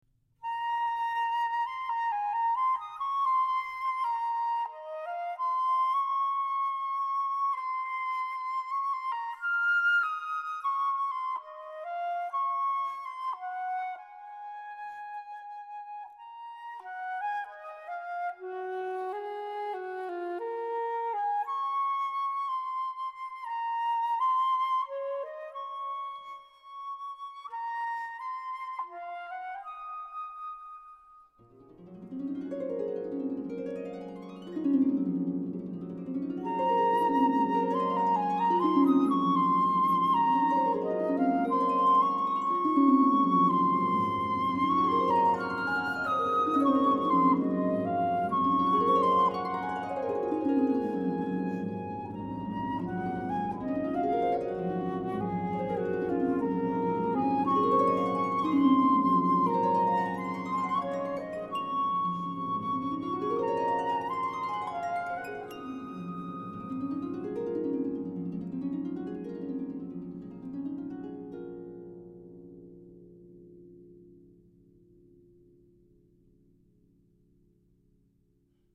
And we’re back for Part 3 of the Spree Forest day trip and the Spreewaldlieder song cycle for flute and harp.
First the flute plays alone, then on the repeat the harp joins in with glissandi in both hands up and down the instrument, perhaps to capture the effect of the breeze on grass fields.